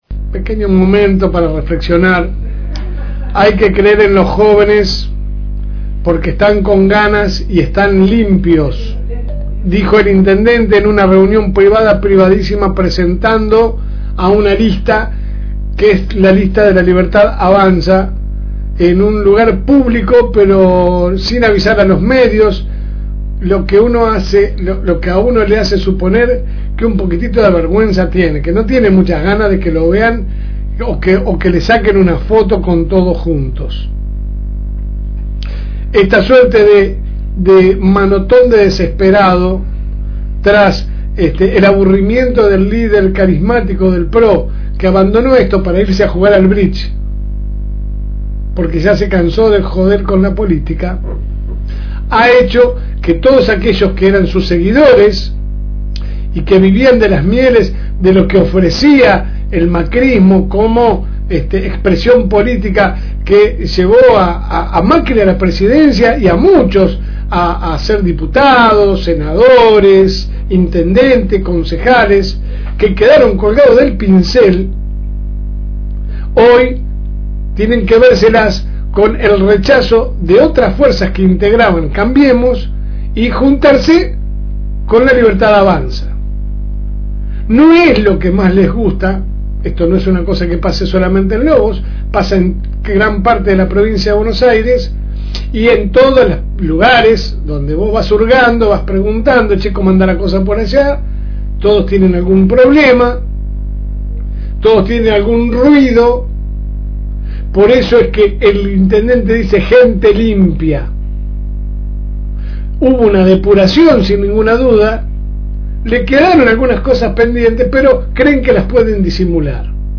Su programa sale de lunes a viernes de 10 a 12 HS por el aire de la FM Reencuentro 102.9